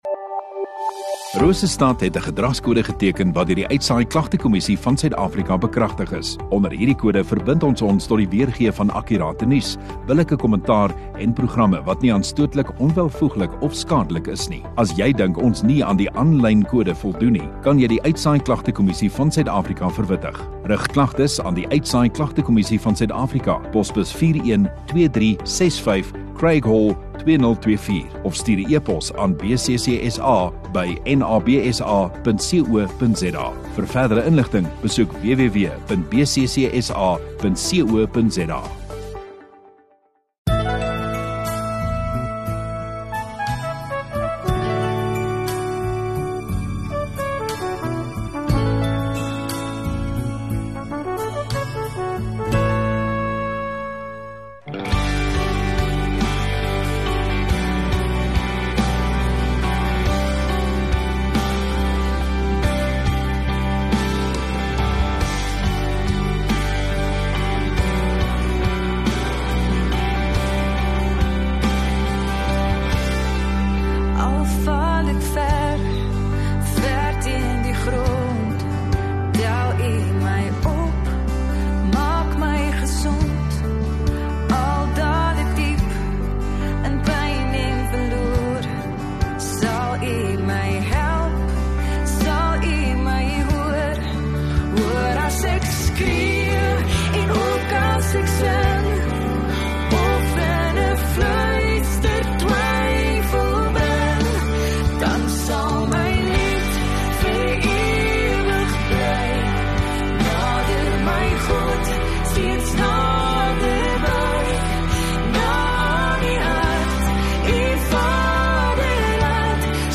12 May MOEDERSDAG Sondagoggend Erediens